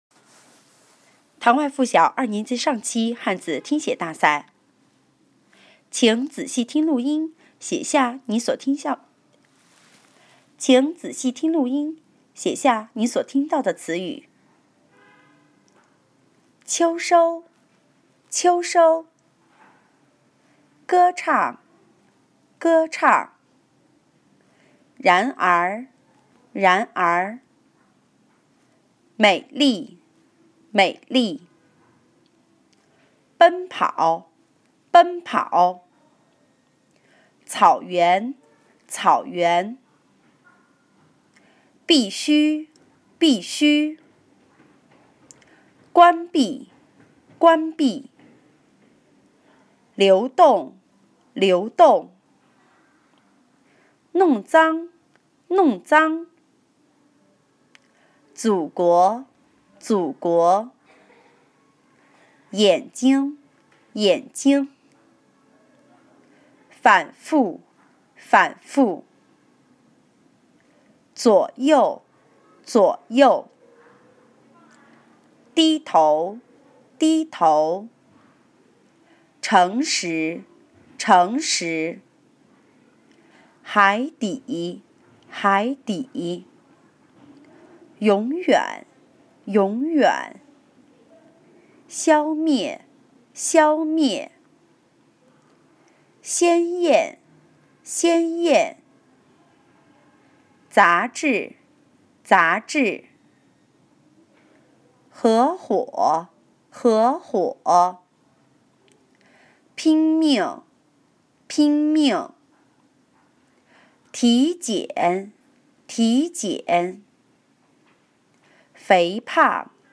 听写大赛语音